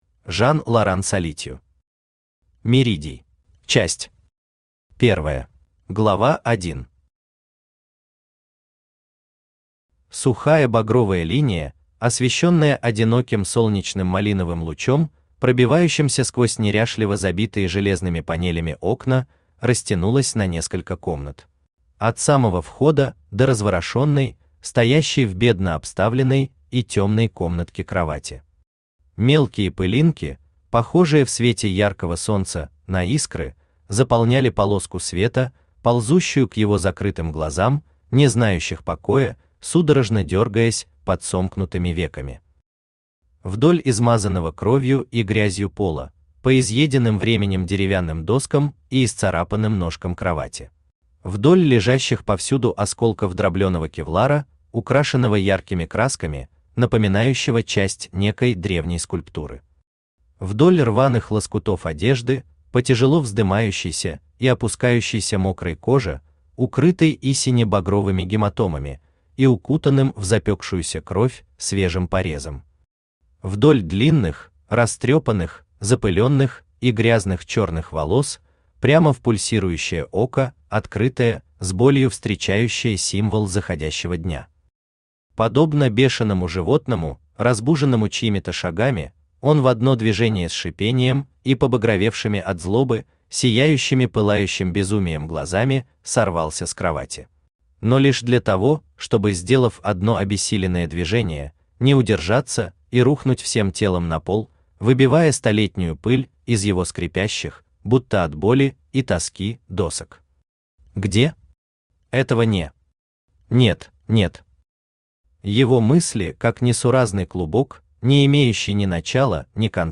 Аудиокнига Меридий | Библиотека аудиокниг
Aудиокнига Меридий Автор Жан-Лоран Солитью Читает аудиокнигу Авточтец ЛитРес.